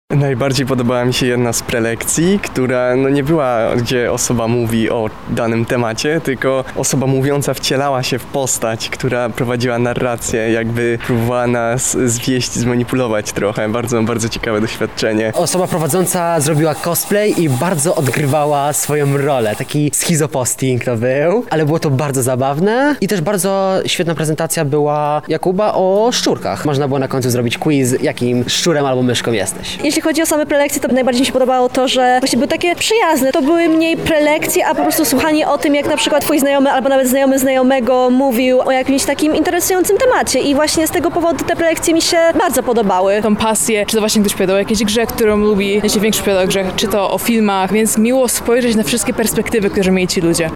Światotkanie, relacja